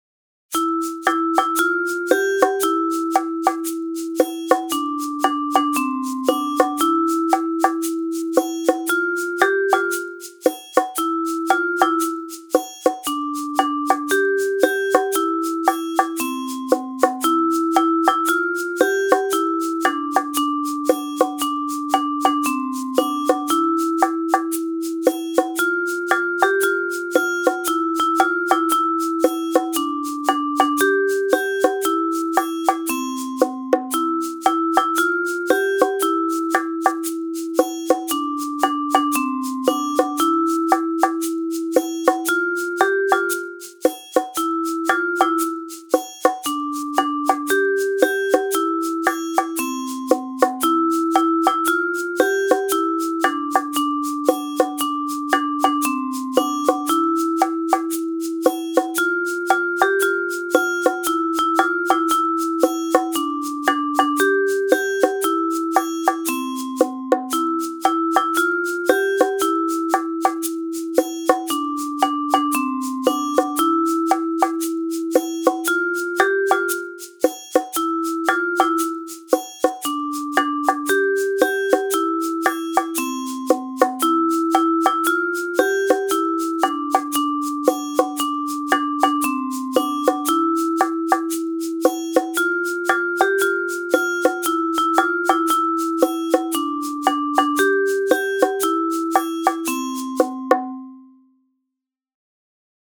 Percussion Ensemble